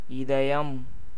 pronunciation) is a 1991 Indian Tamil-language romantic drama film directed by Kathir in his directorial debut.